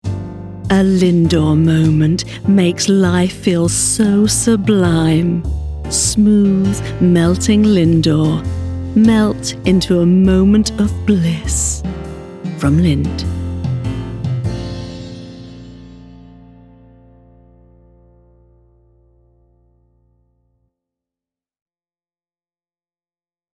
Voice Overs for TV and Radio Commercials
Lindor Voice Over